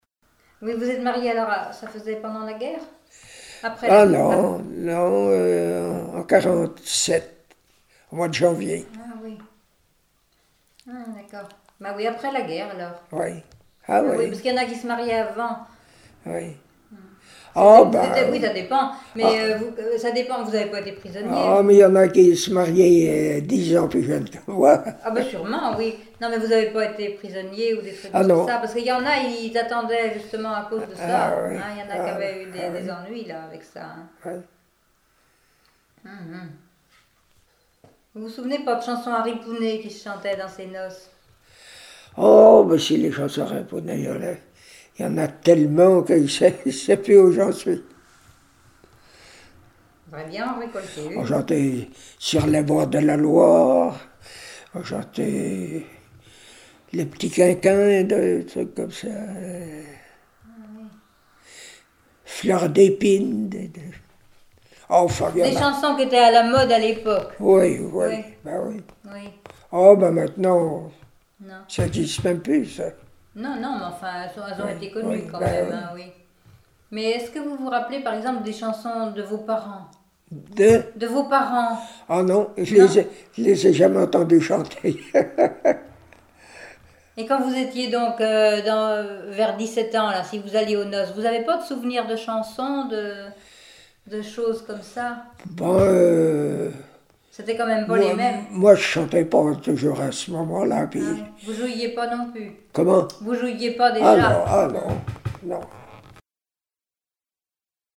chanteur(s), chant, chanson, chansonnette
Catégorie Témoignage